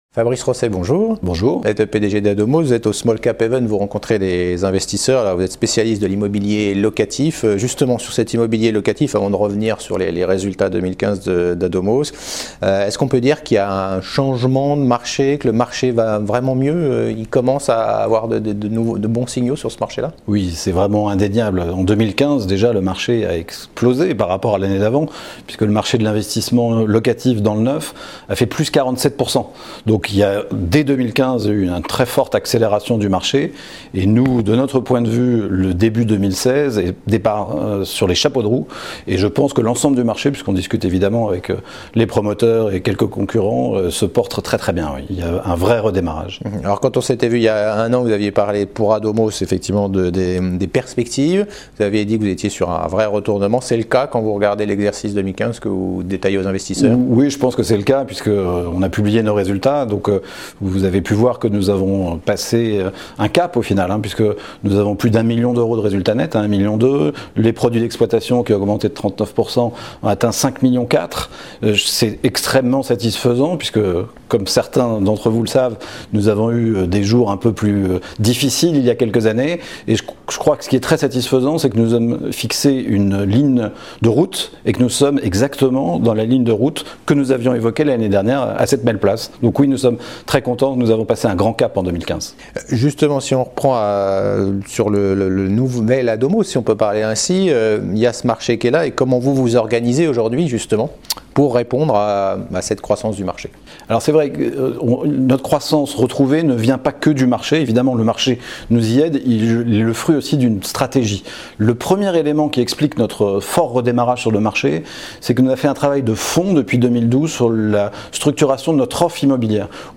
La Web Tv partenaire media du SmallCaps Event organisé par CF&B Communication à Paris pour des interviews de dirigeants.